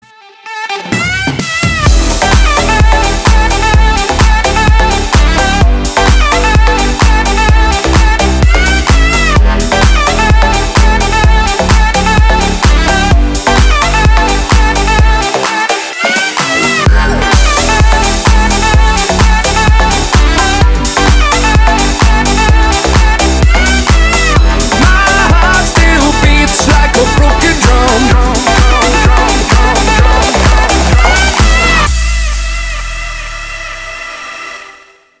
• Качество: 320, Stereo
dance
club
Саксофон
Стиль: House